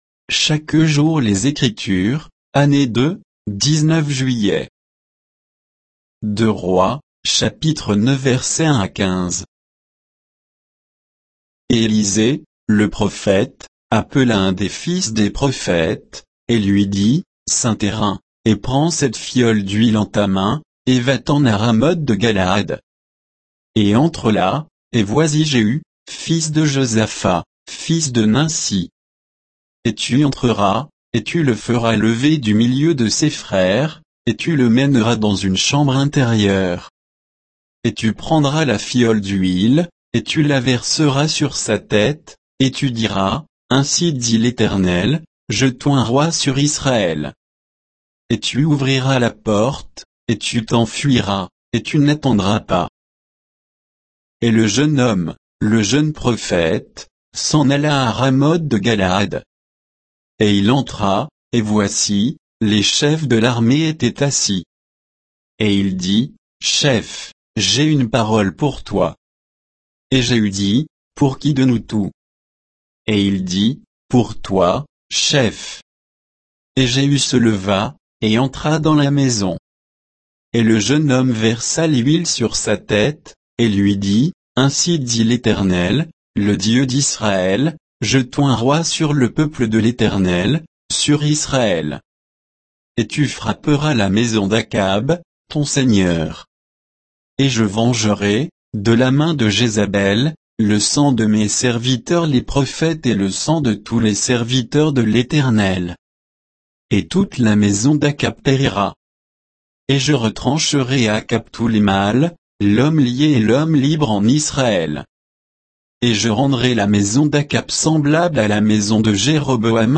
Méditation quoditienne de Chaque jour les Écritures sur 2 Rois 9